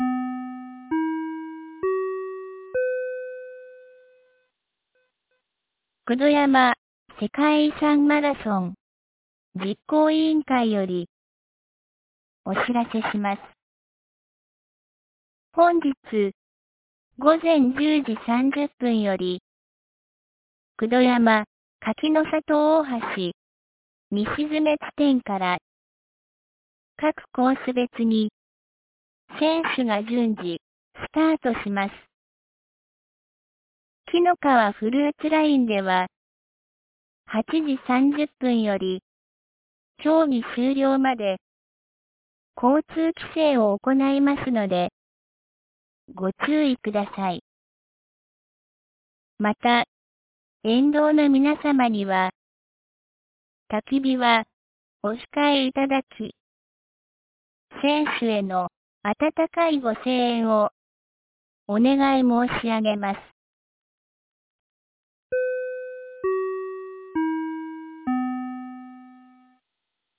2025年01月19日 08時16分に、九度山町より全地区へ放送がありました。